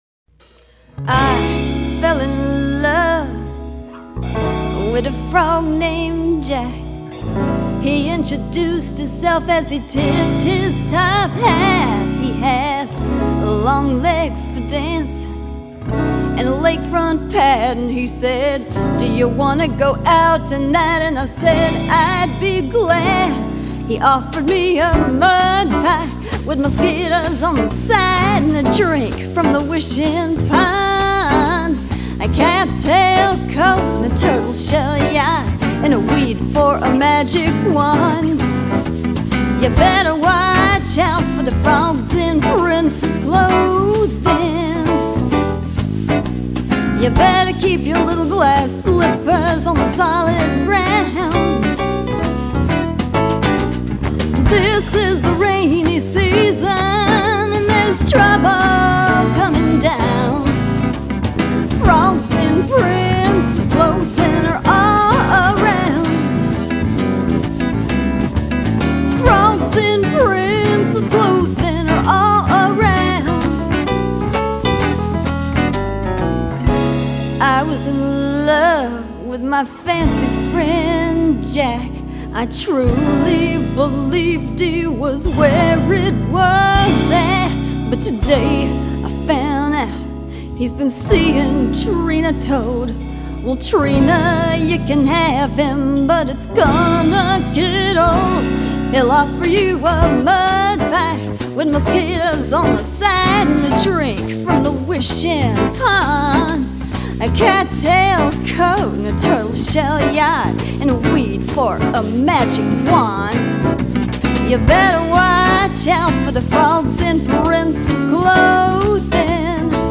Funny or Quirky Songs
(About: Humor & blues, kissing frogs)